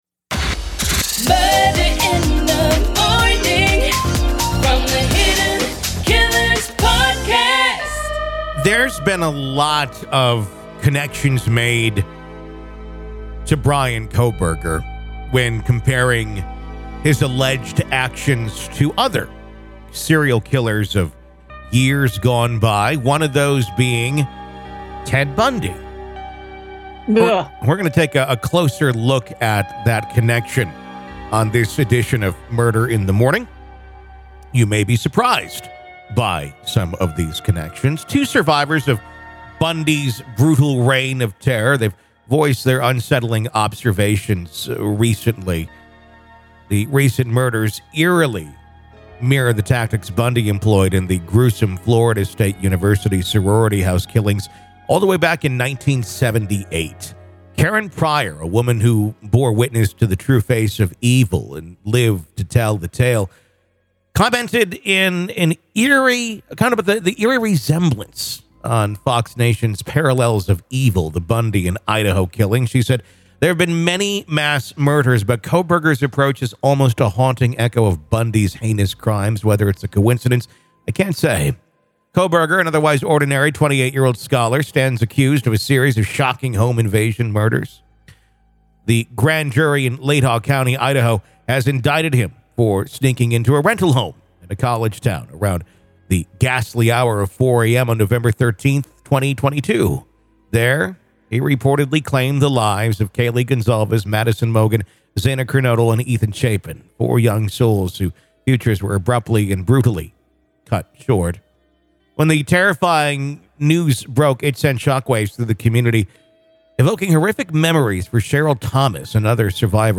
Their conversation offers deep insights into the psychology behind such heinous crimes, providing a captivating, albeit chilling, exploration of human nature.